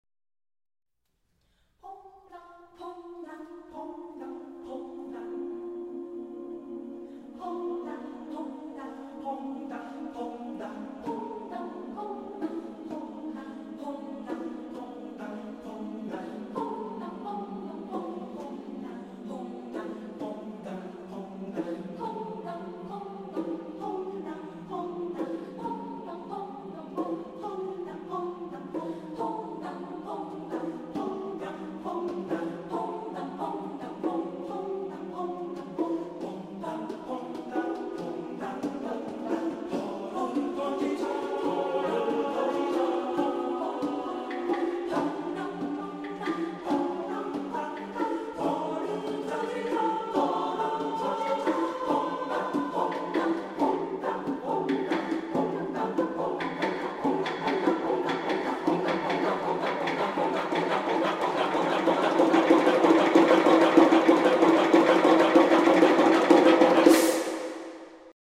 Composer: Korean Folk Song
Voicing: SSAA a cappella